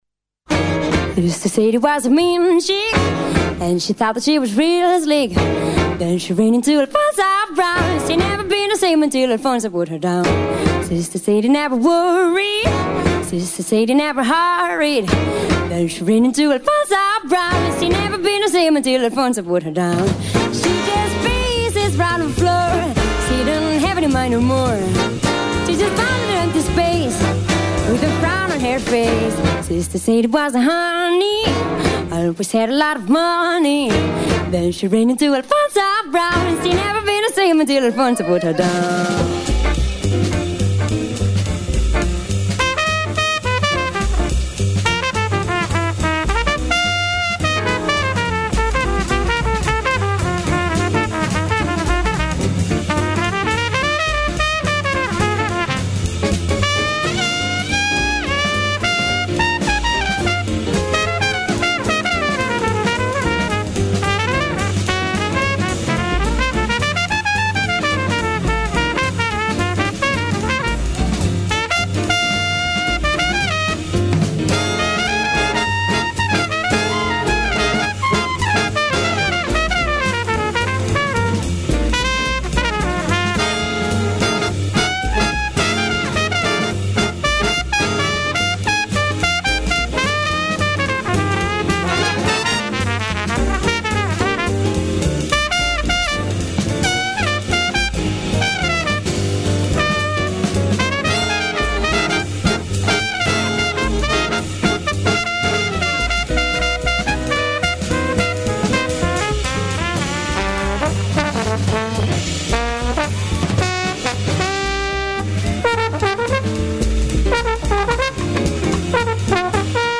Festival Jazz à l'heure d'été
chant, trompette, saxophone
guitare
contrebasse